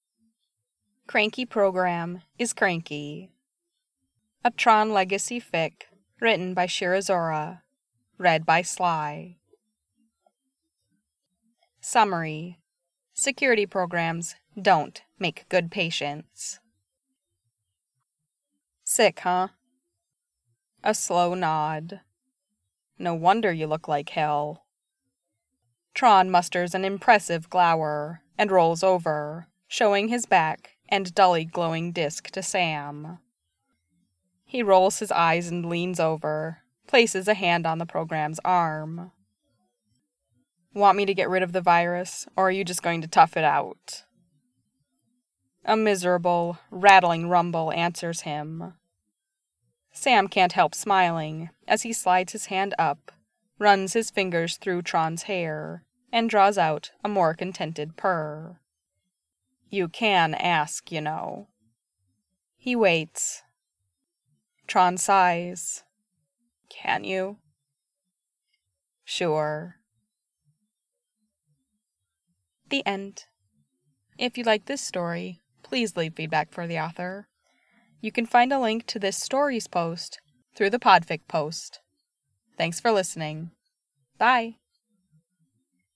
Three podfic: all Tron Legacy, all for Virtual TronCon